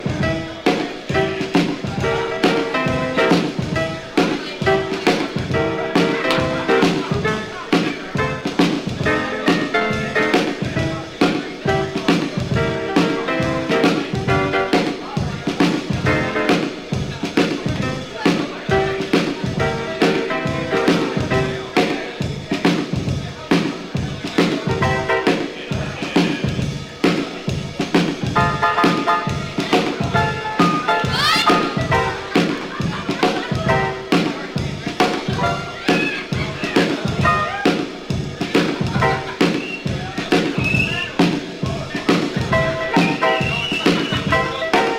Rock'N'Roll, Jazz　USA　12inchレコード　33rpm　Mono